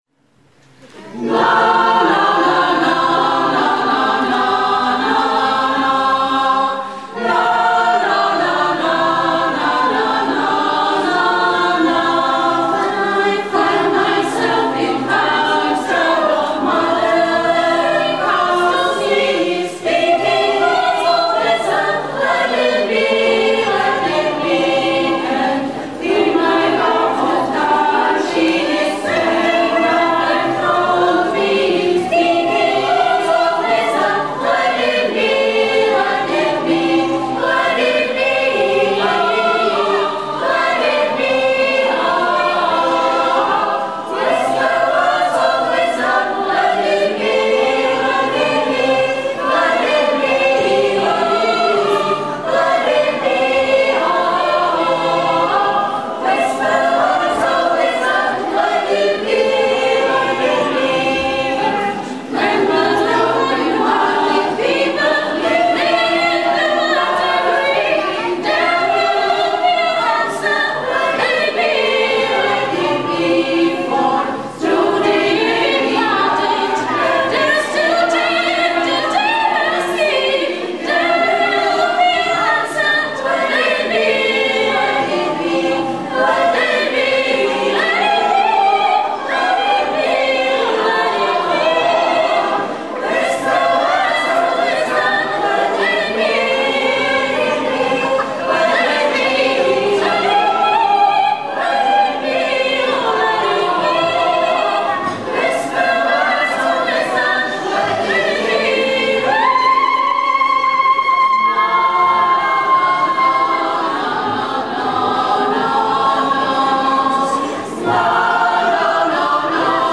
Živě z akce ZUŠ Zliv - 60. léta ze dne 8.11.2007
Sníženou kvalitu zvuku nahrazuje atmosféra